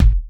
Kick _01.wav